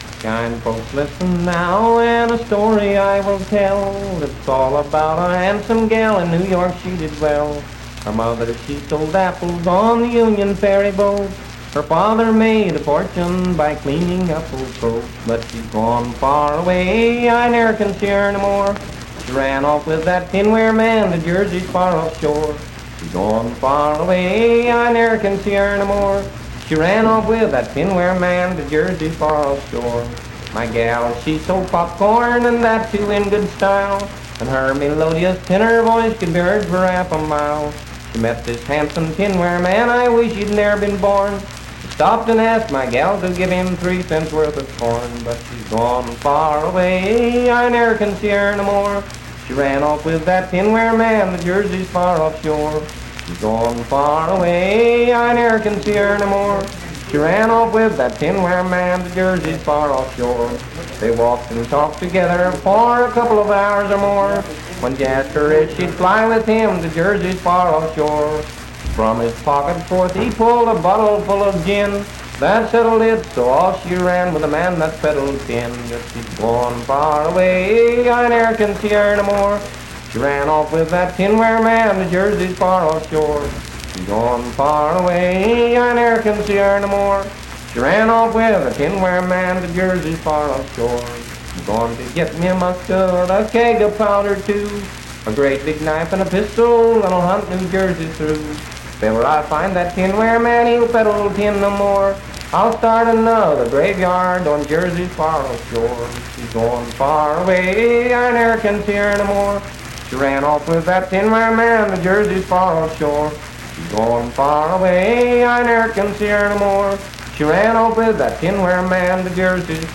Unaccompanied vocal performance
Verse-refrain 4(4) &R(4).
Voice (sung)